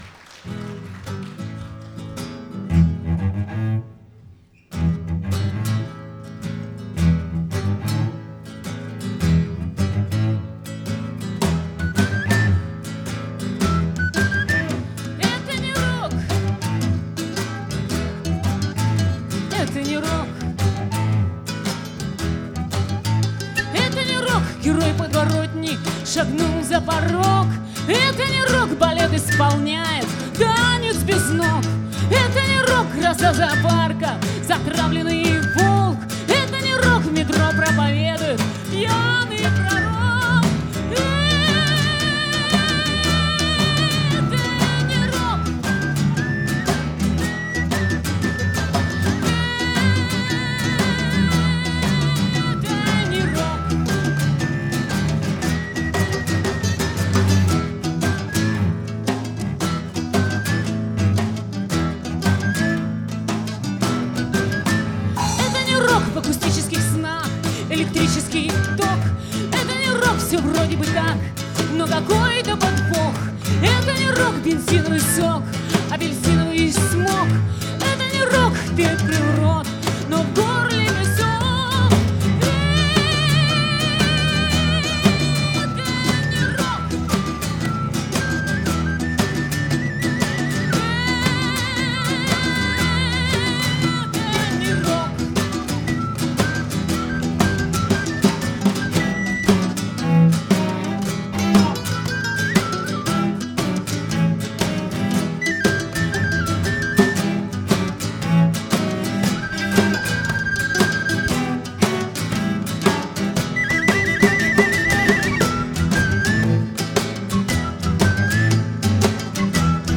Концертный двойник, стиль — акустика.
виолончель, голос
флейты, гитары, перкуссия, голос
мандолина, голос